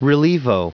Prononciation du mot relievo en anglais (fichier audio)
Prononciation du mot : relievo